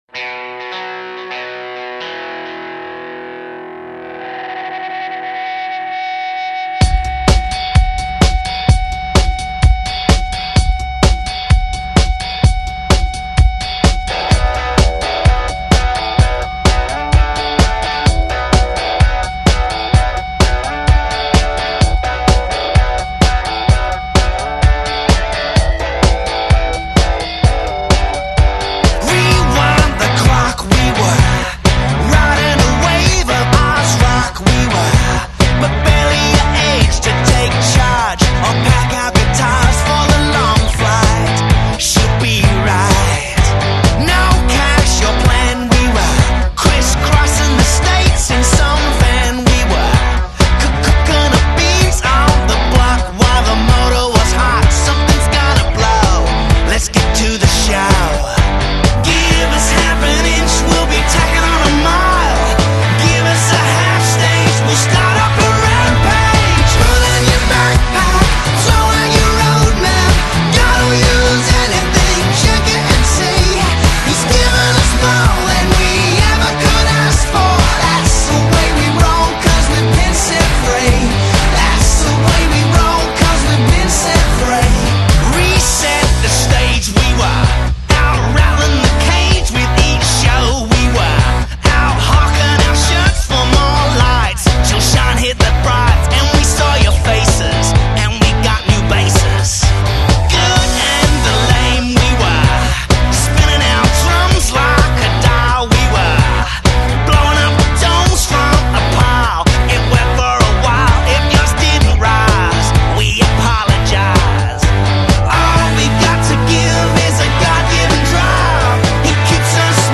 humorous recap